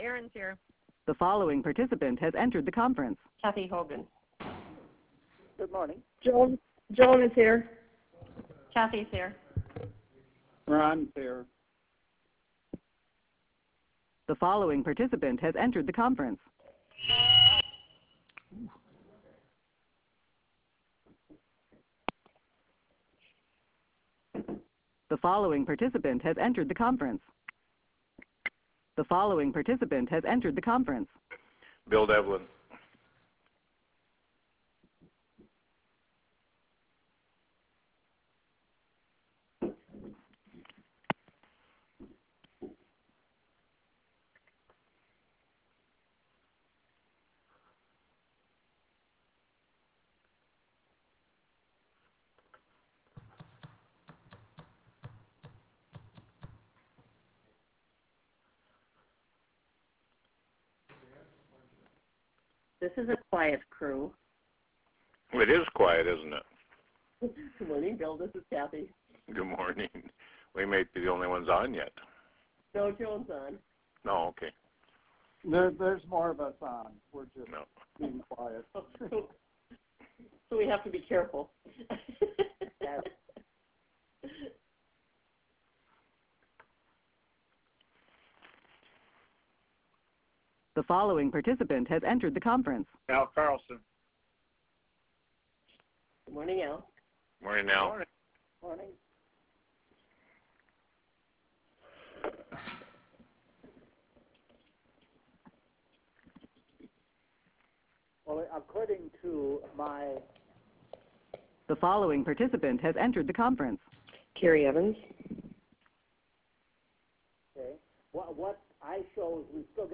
This meeting will be conducted by teleconference call connection.
Prairie Room State Capitol Bismarck, ND United States